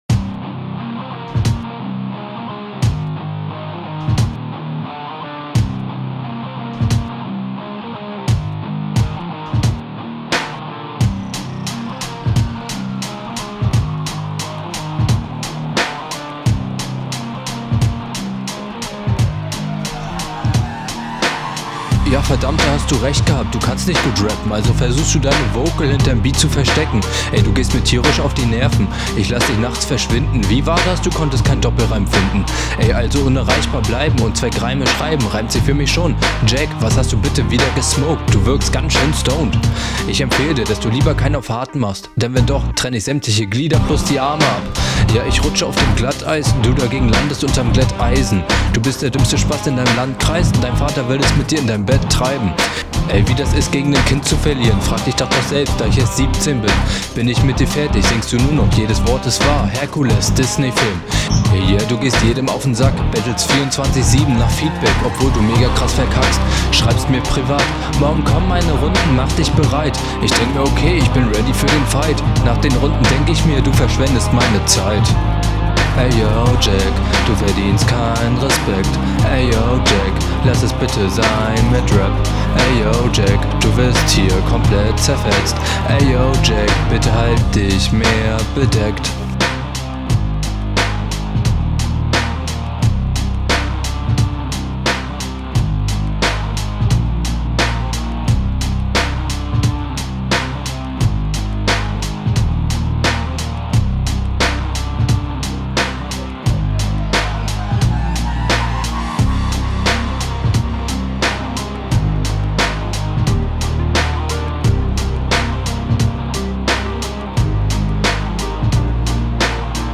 Irgendwie kommen ein paar Konter mit der ziemlich eigenen Delivery ganz cool sogar.